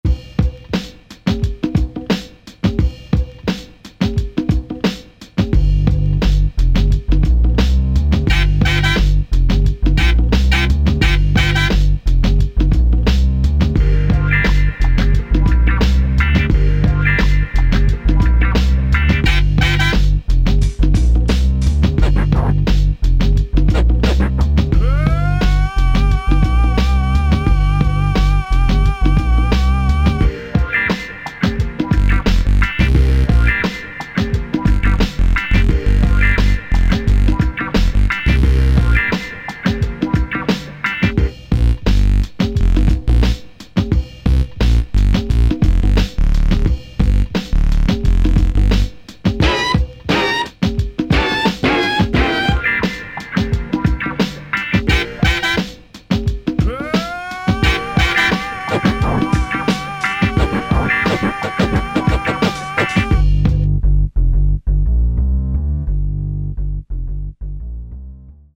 80 CitySummer Slow groove, very nice